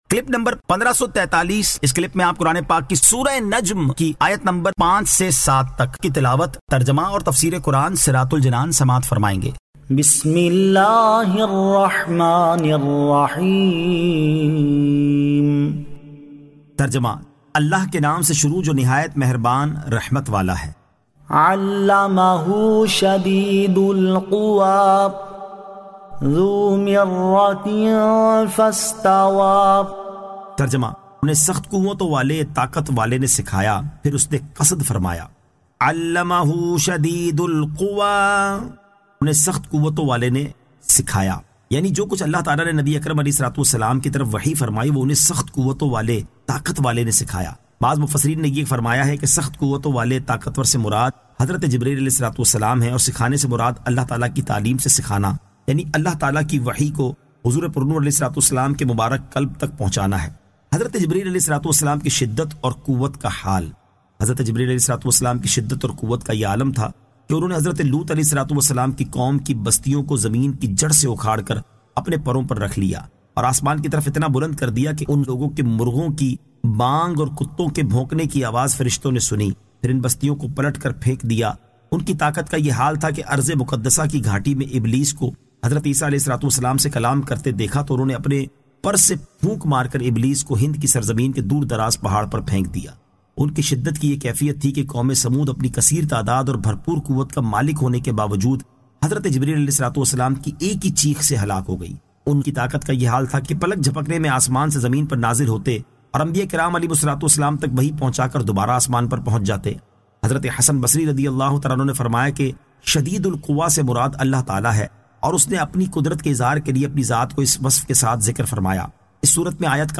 Surah An-Najm 05 To 07 Tilawat , Tarjama , Tafseer